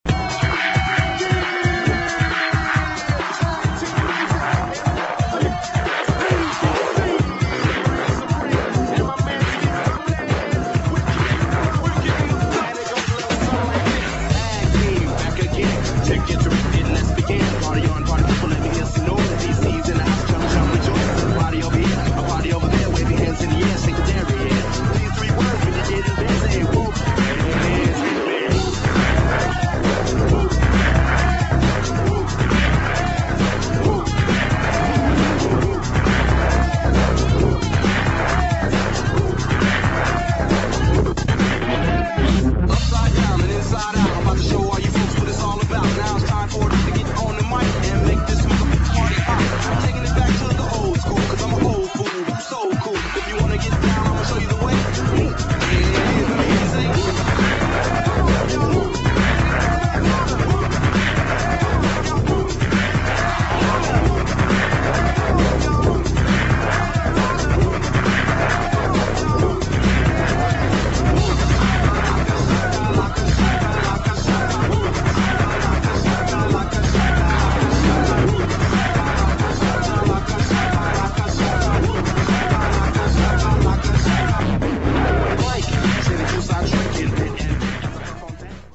[ BREAKS / HIP HOP ]